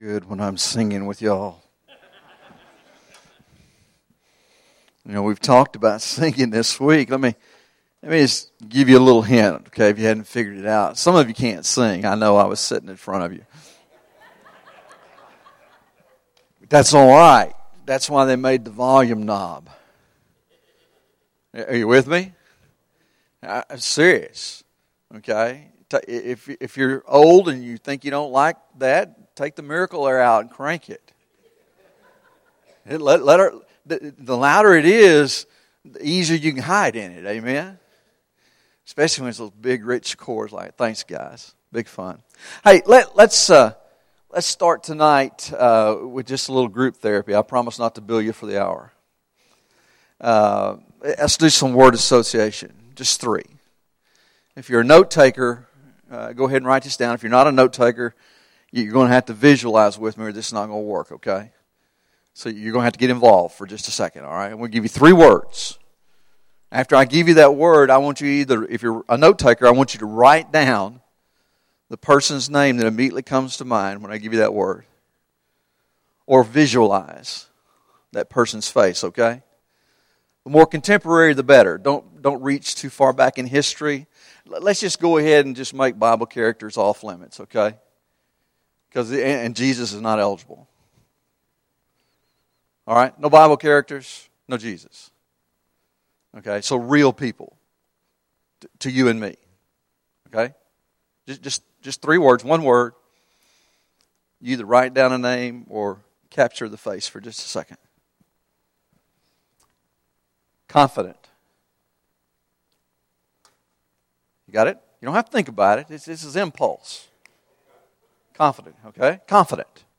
Messages by pulpit guests and special occasion sermons by pastor